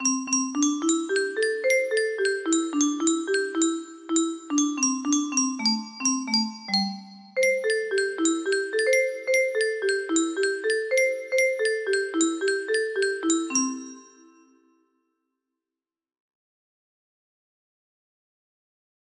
pentatonic music box melody